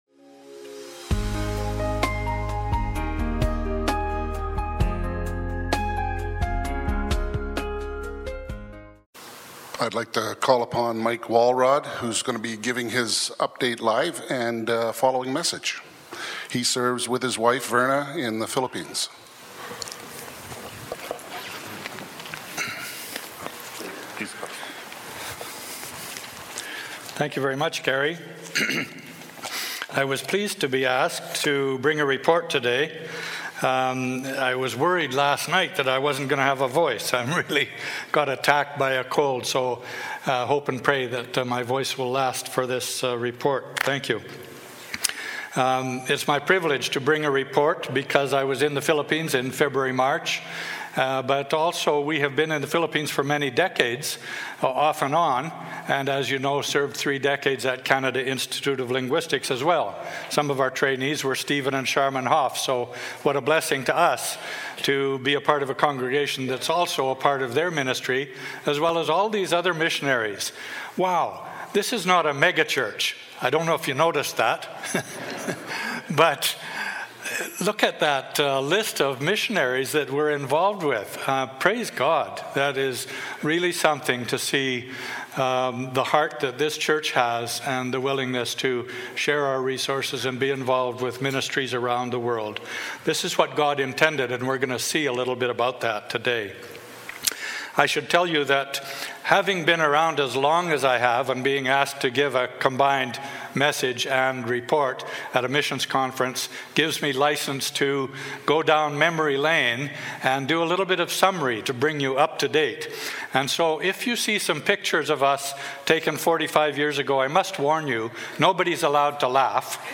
Sermons | Grace Church